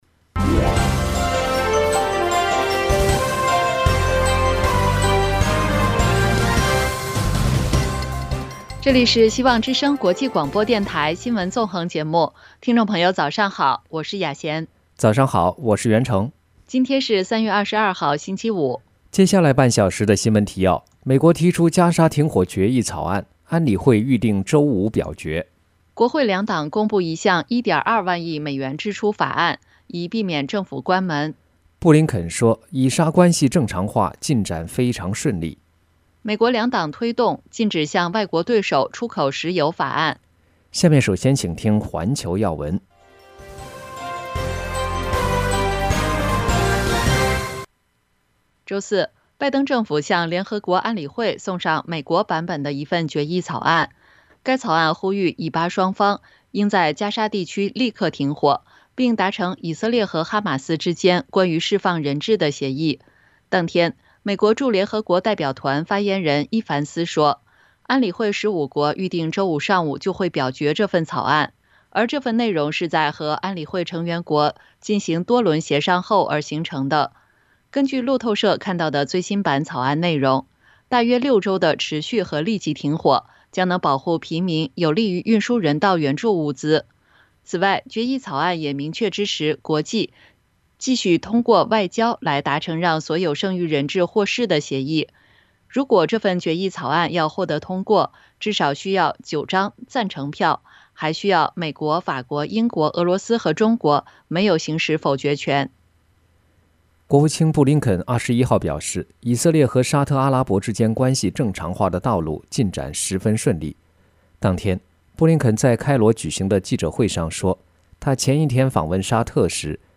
希望之聲 - FM96.1 灣區台